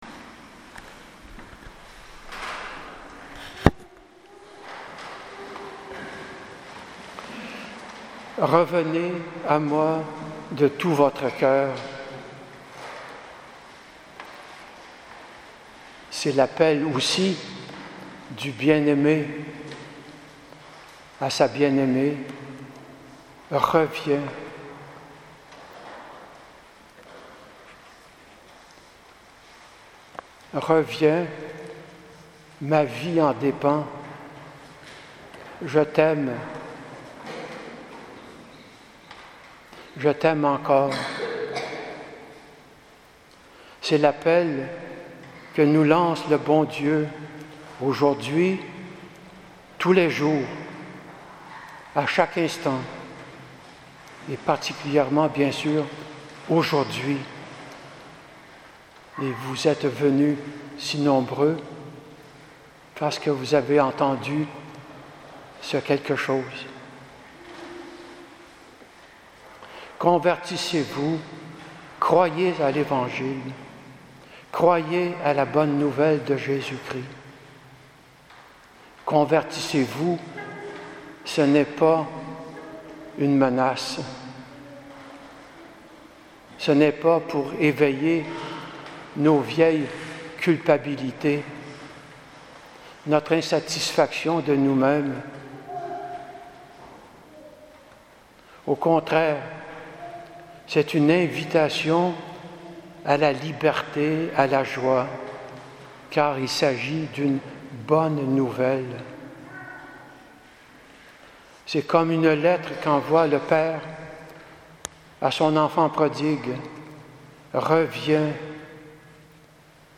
Homélie du mercredi des cendres 2018